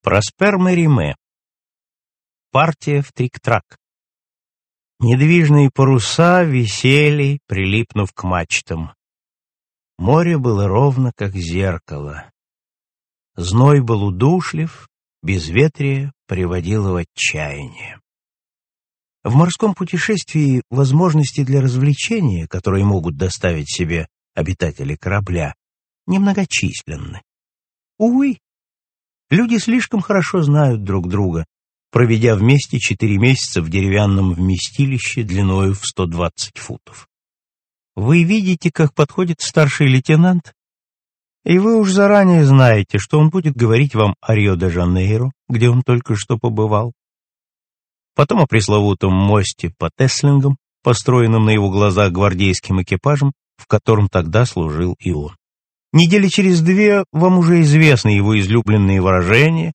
Мериме Проспер - Партия в триктрак (исполняет А.Филозов) Мериме Проспер - Партия в триктрак (исполняет А.Филозов) Продолжительность: 50:3